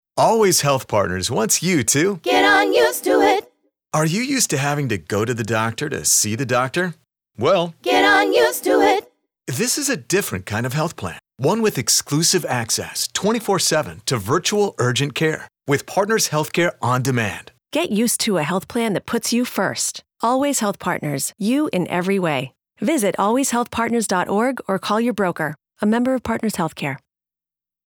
A series of thirty-second radio spots aimed at conveying Allways Health Partners member-centric benefit offerings.